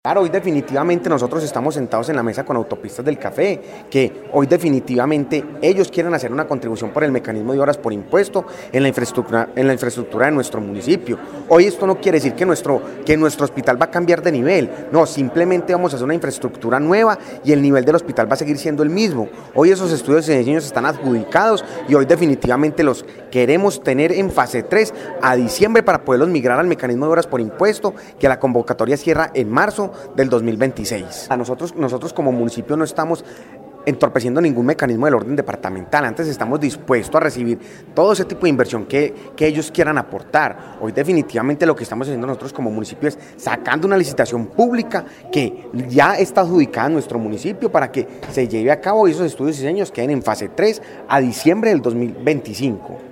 Alcalde de Salento